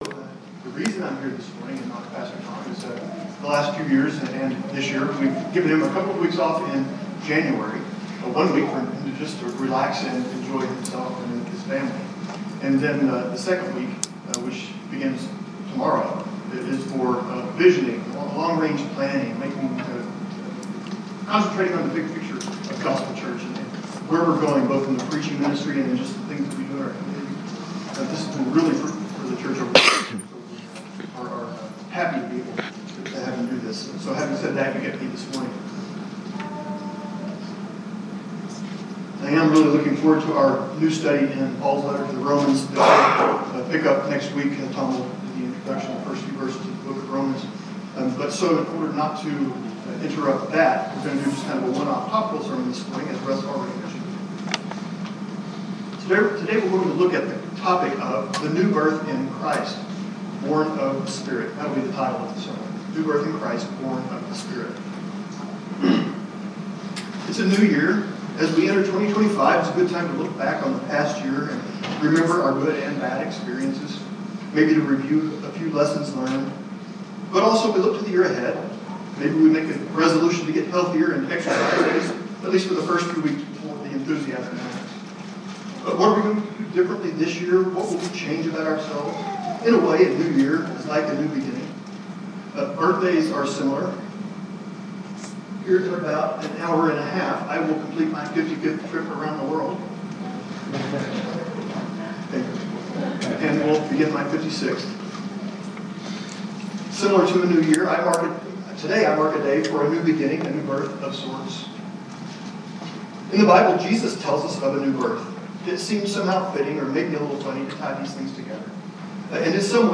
Location: Gospel Church Durango